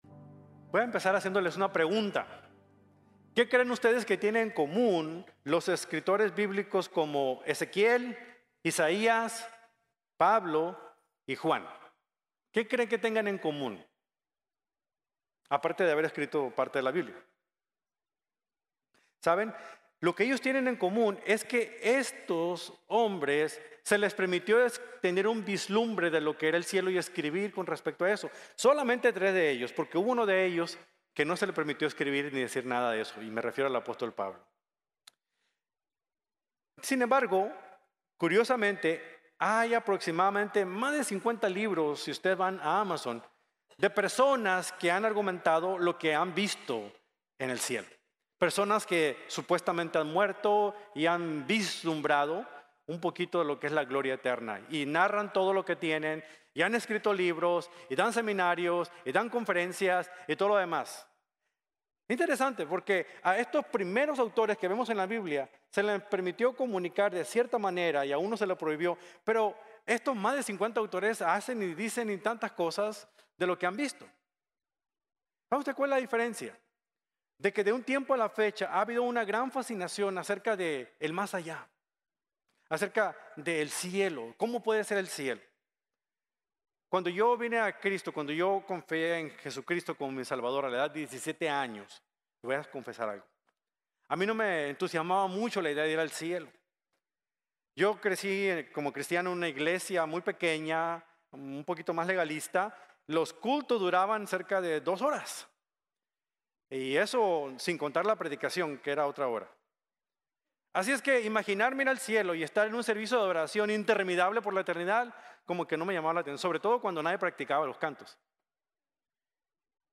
Dignos de Toda Alabanza | Sermon | Grace Bible Church